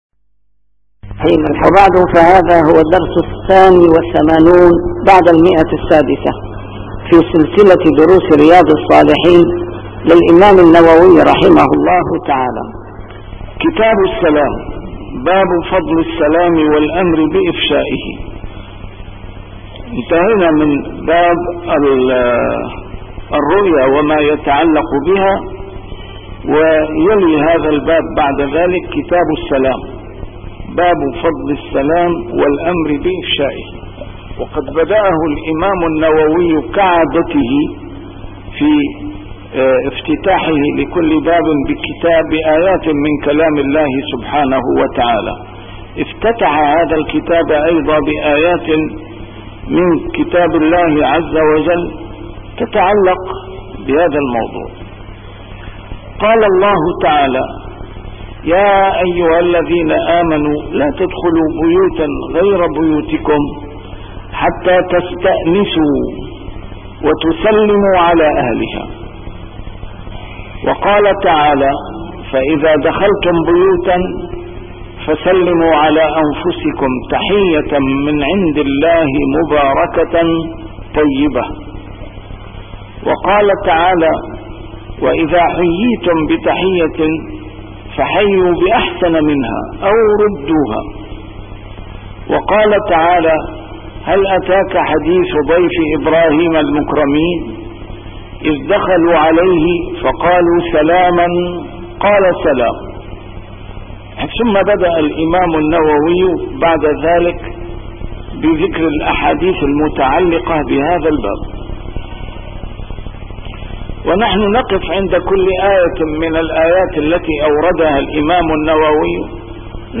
A MARTYR SCHOLAR: IMAM MUHAMMAD SAEED RAMADAN AL-BOUTI - الدروس العلمية - شرح كتاب رياض الصالحين - 682- شرح رياض الصالحين: فضل السلام